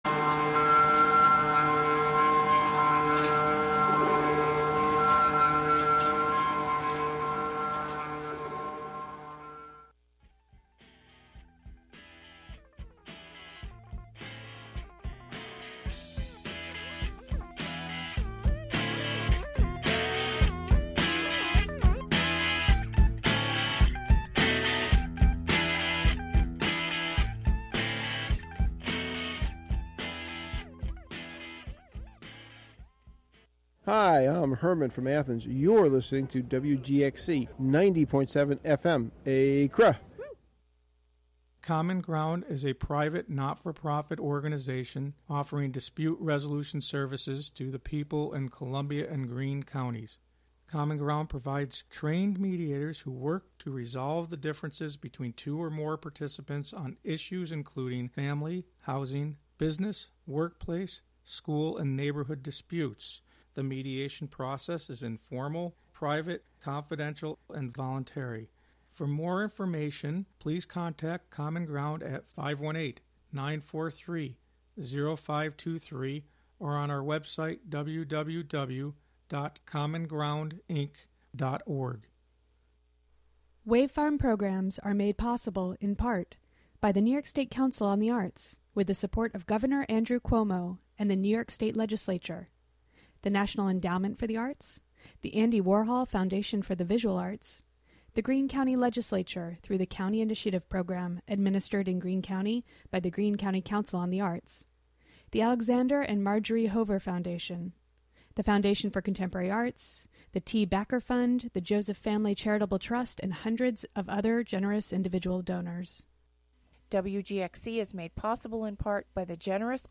Monthly program featuring music and interviews.